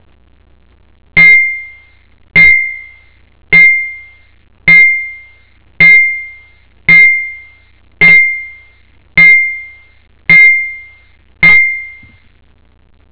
The sound you hear is a heavy spoon clinking against a heavy coffee cup.
clink.wav